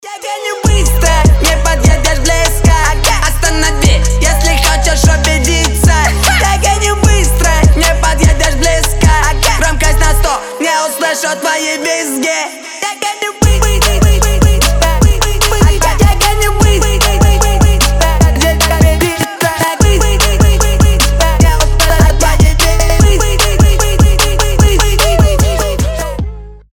Хип-хоп
мощные басы
качающие